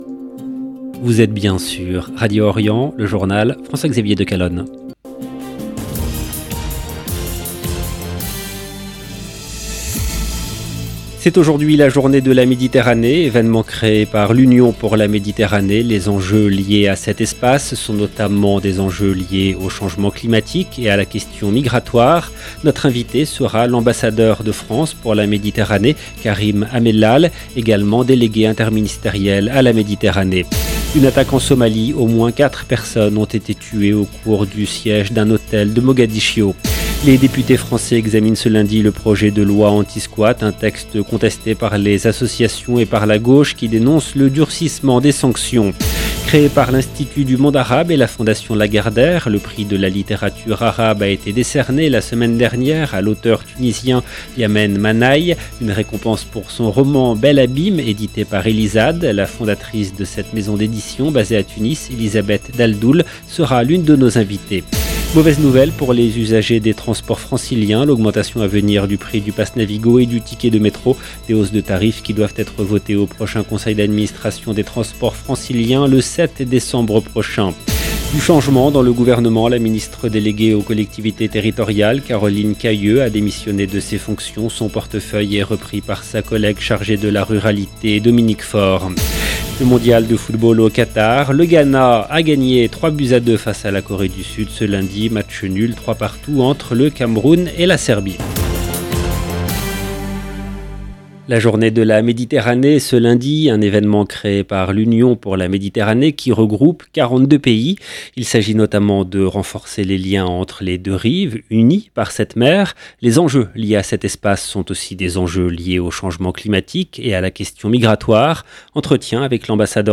Karim Amellal football 28 novembre 2022 - 16 min 54 sec LE JOURNAL DU SOIR EN LANGUE FRANCAISE DU 28/11/22 LB JOURNAL EN LANGUE FRANÇAISE C’est aujourd’hui la journée de la Méditerranée.
Notre invité sera l’ambassadeur de France pour la Méditerranée Karim Amellal, également délégué interministériel à la Méditerranée.